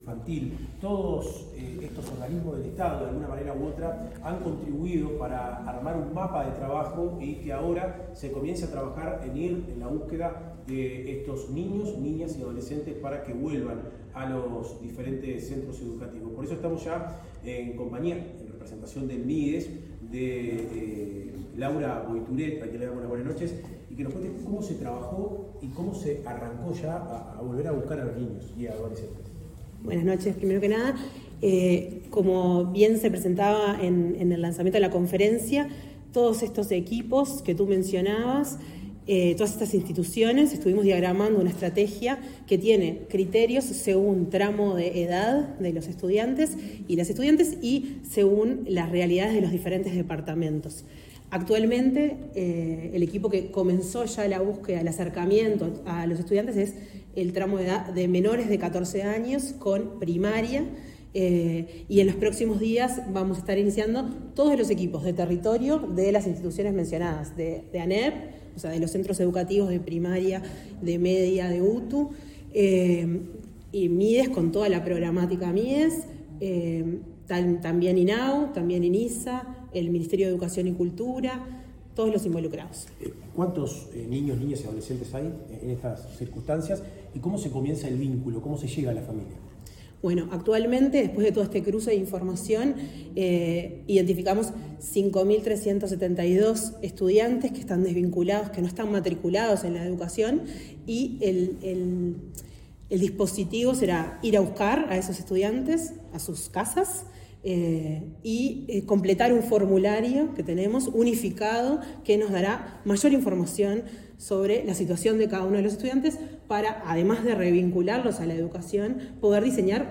Se realizó conferencia de prensa lanzando la búsqueda de más de 5000 chicos que están desconectados de la educación en el Ministerio de Desarrollo Social juntos a INAU, ANEP y del MEC Educación inicial y Primaria
CONFERENCIA DE PRENSA DEL MIDES JUNTO A INAU Comparte esto: Facebook WhatsApp Twitter Telegram Skype